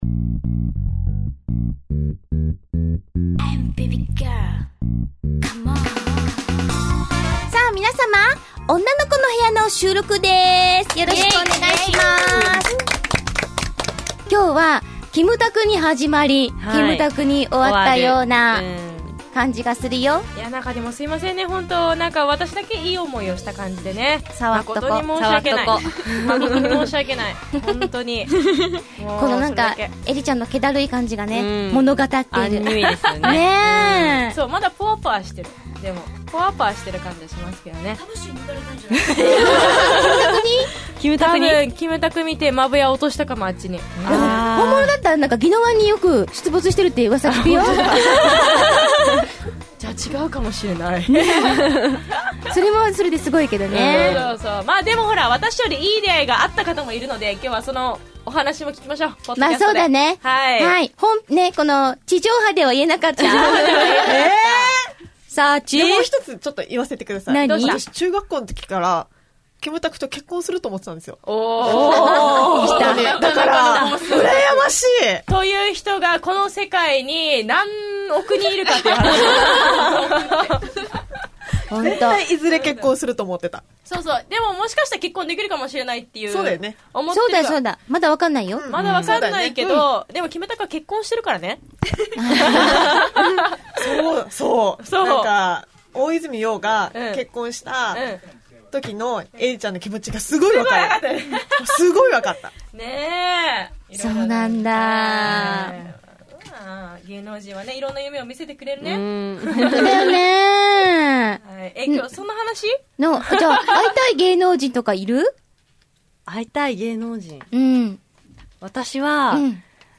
ＦＭ沖縄:毎週土曜日深夜０時スタート(30分) 生放送でーす。